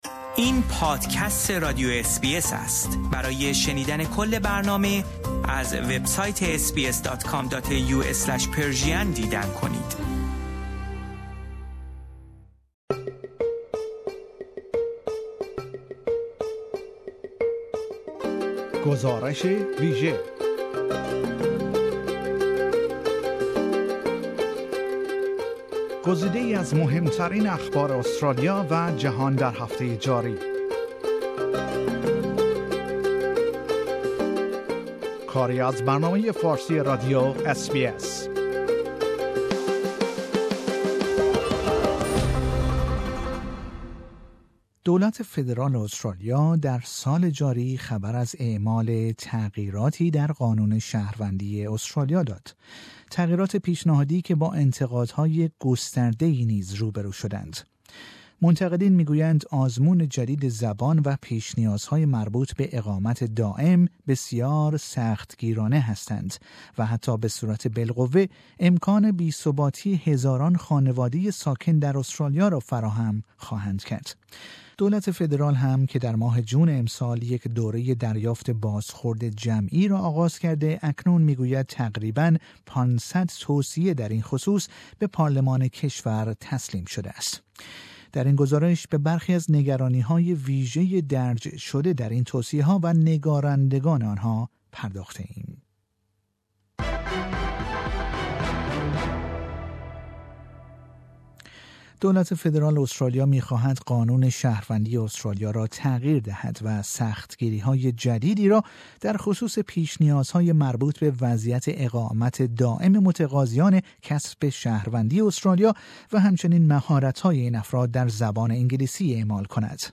در این گزارش به برخی از نگرانی های ویژه درج شده در این توصیه ها و نگارندگان آنها پرداخته ایم.